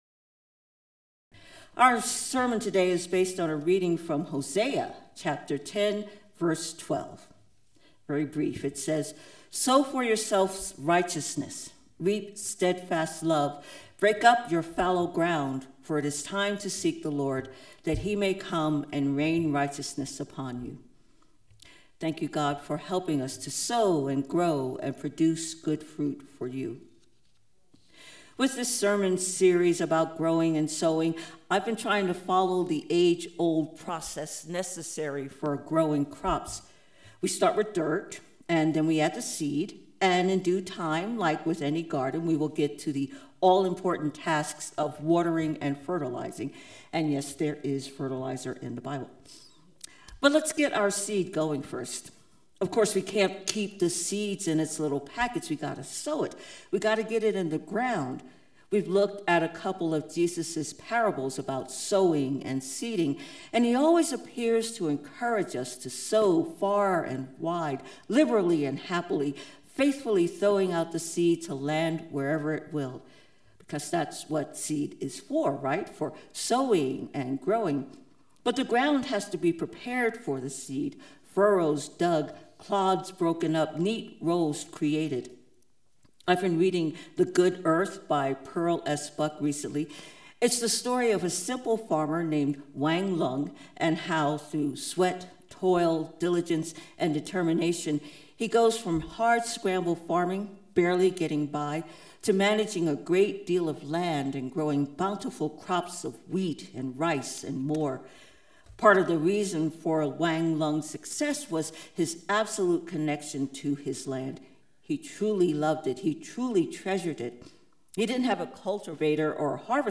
Sermon text: Hosea 10: 12